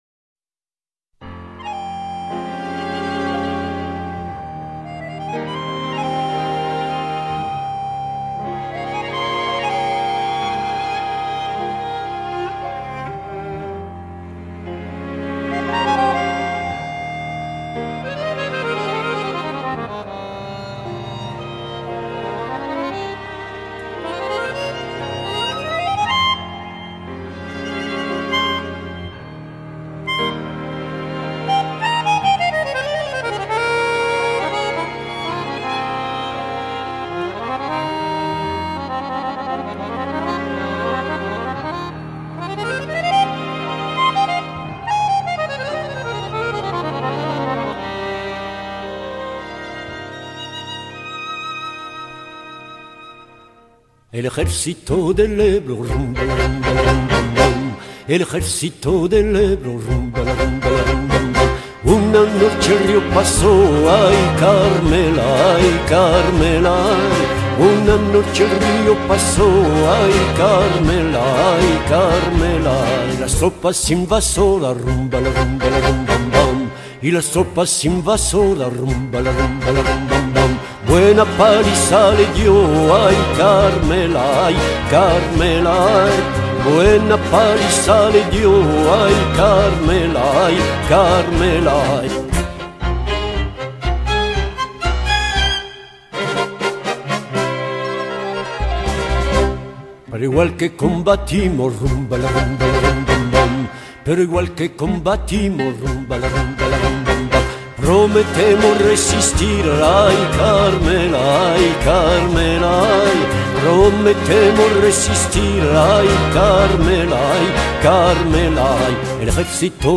En 1997, le chanteur ténébreux gravera sur disque El paso del Ebro, une chanson traditionnelle datant du temps des guerres napoléoniennes qui a été repris par les Républicains lors de la guerre civile espagnole. Cependant, pour marquer l’aspect mélancolique de la pièce et renforcer sa nostalgie, Escudero interprétera la chanson sur une musique de tango, et non comme un paso doble traditionnel.